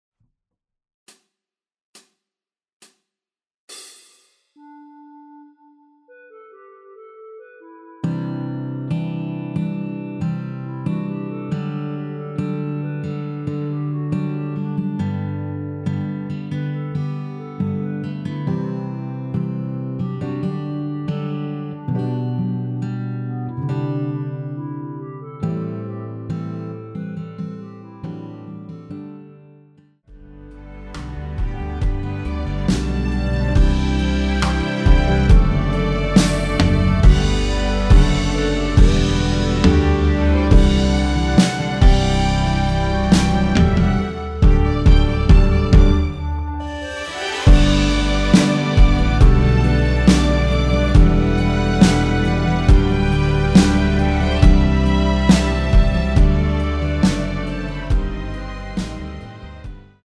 전주 없이 시작하는 곡이라서 시작 카운트 만들어놓았습니다.(미리듣기 확인)
원키에서(-1)내린 멜로디 포함된 MR입니다.
앞부분30초, 뒷부분30초씩 편집해서 올려 드리고 있습니다.
중간에 음이 끈어지고 다시 나오는 이유는